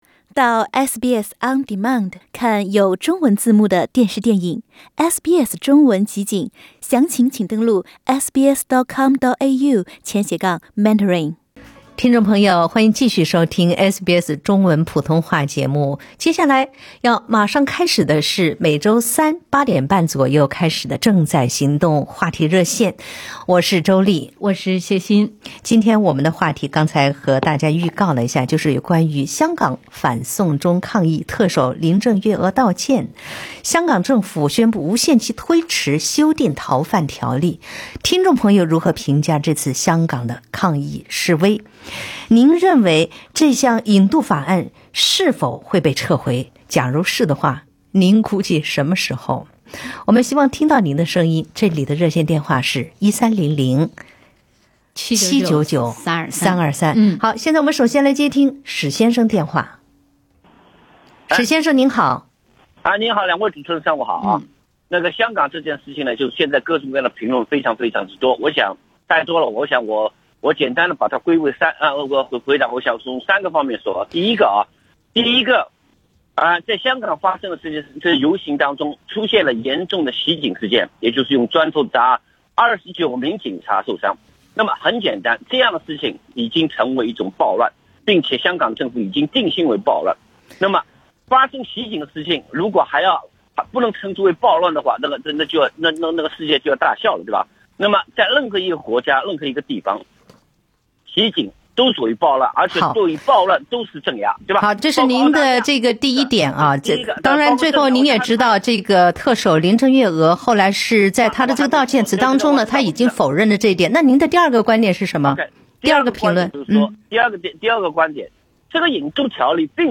香港反送中抗议，特首林郑月娥道歉，港府宣布无限期推迟修订《逃犯条例》，您如何评价这次抗议示威？ SBS普通话电台时政热线栏目《正在行动 – 你对香港反送中示威的看法是什么？》 现场听众评论各抒己见，观点各异。电台节目内容剪辑，欢迎点击收听。